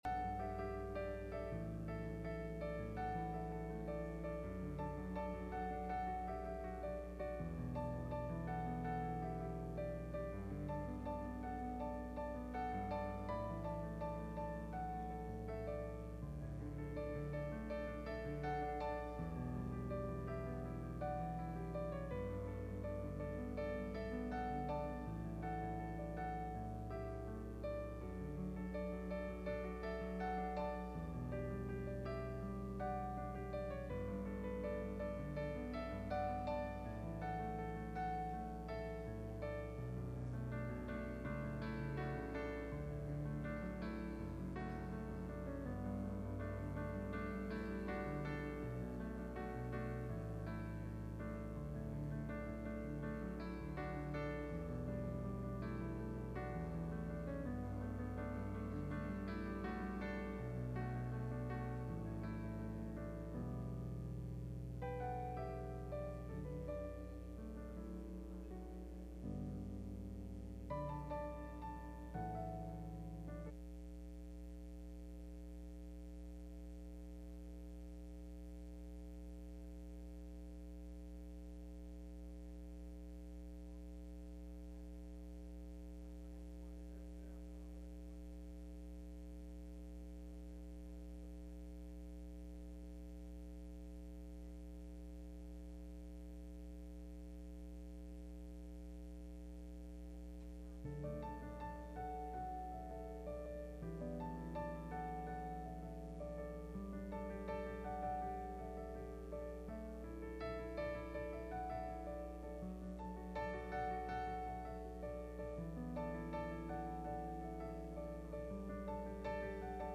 Luke 24:28 Service Type: Midweek Meeting « A Hero Named Caleb